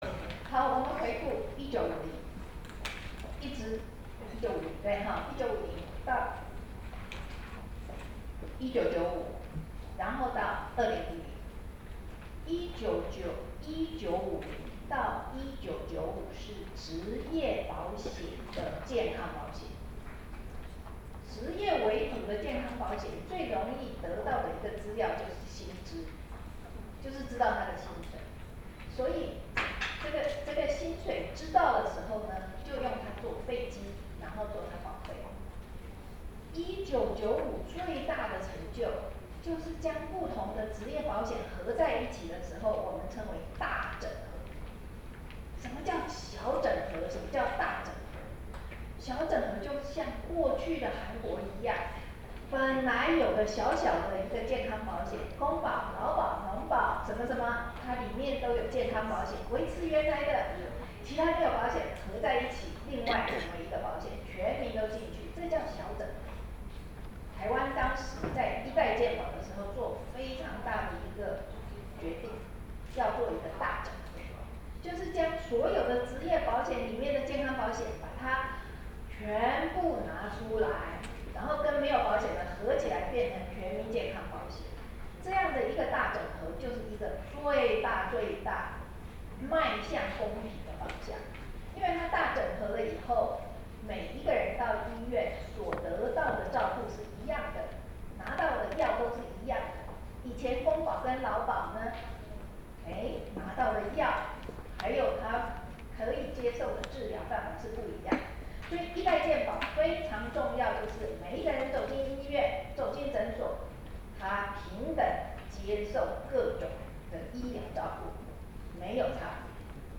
「健保改革不能退怯  修法時機必須掌握」記者會
發言錄音檔